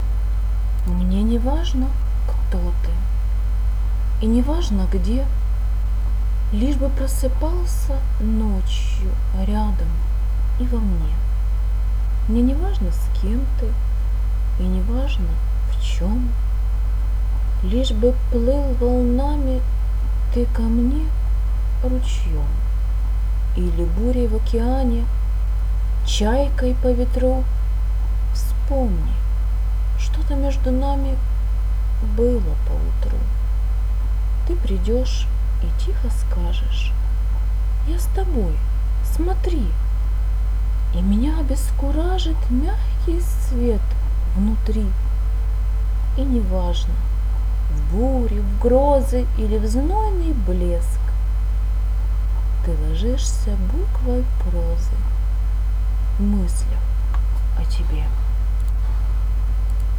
очень мило! у тебя хороший голос.